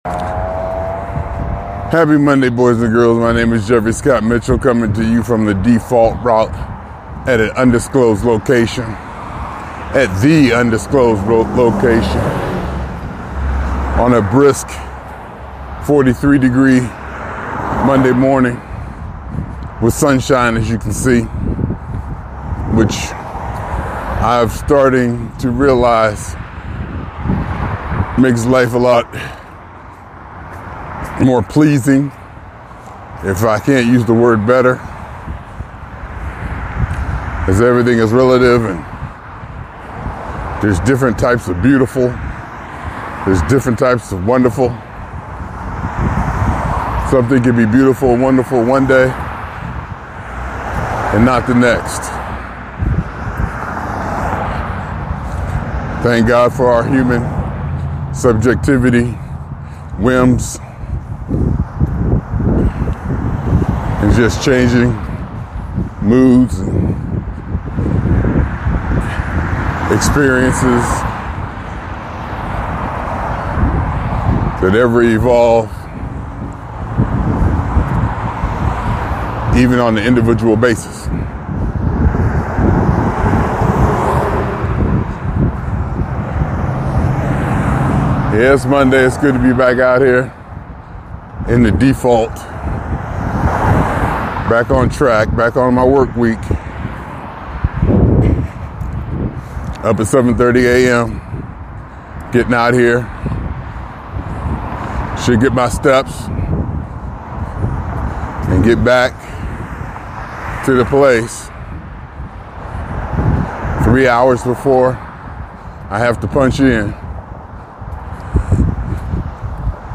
and personal responsibility on a Monday morning walk.